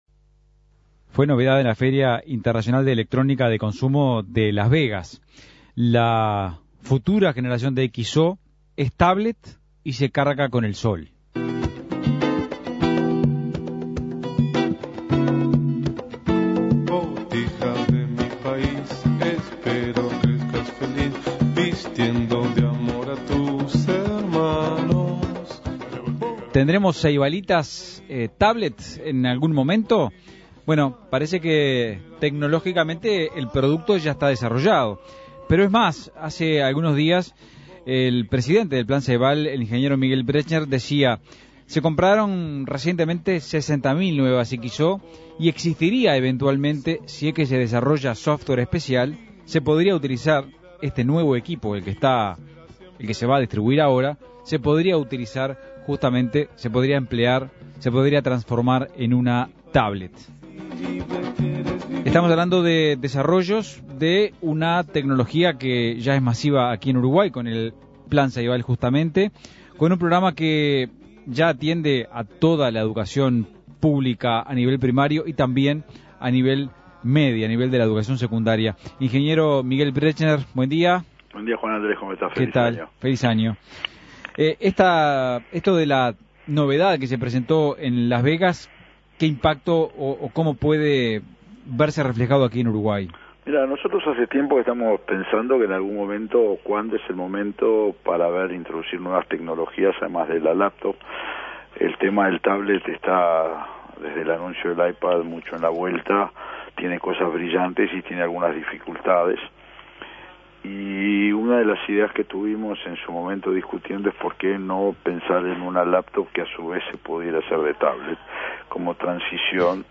Entrevista a Miguel Brechner